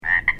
The Sierran Treefrog produces two different kinds of very loud advertisement calls: a two-part, or diphasic call, typically described as rib-it, or krek-ek, with the last syllable rising in inflection, and a one-part, or monophasic call, also called the enhanced mate attraction call.
Diphasic (two-part) Call
Sound  This is a recording of one repetition of the advertisement call of a Sierran Trefrog recorded at night in San Joaquin County.